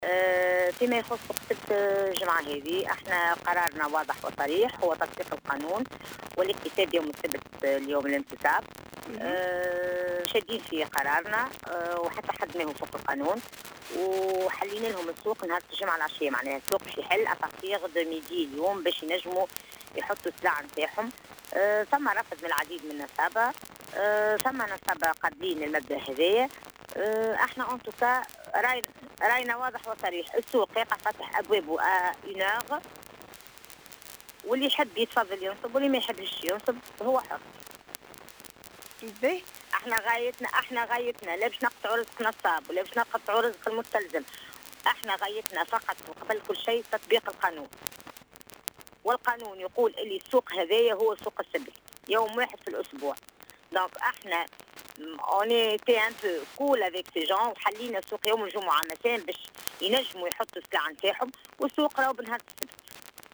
تصريح معتمد حمام سوسة منية الجويني